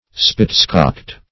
Spitscocked \Spits"cocked`\, a.